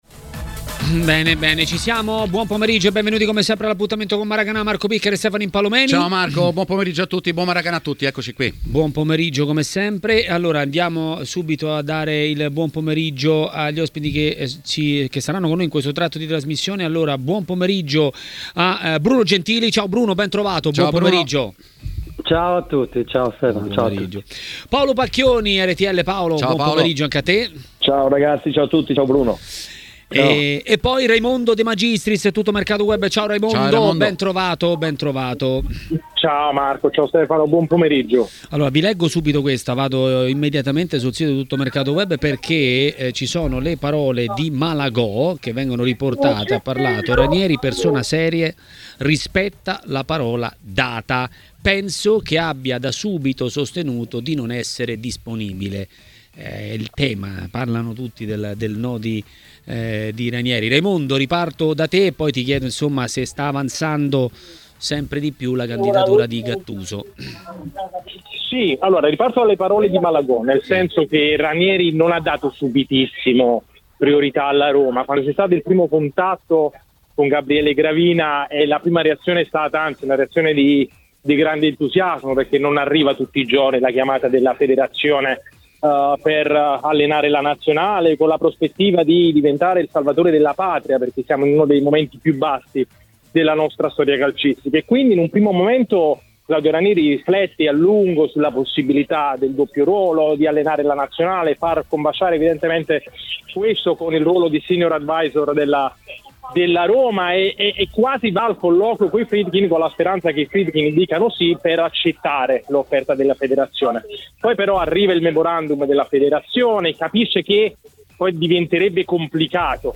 è intervenuto a TMW Radio, durante Maracanà.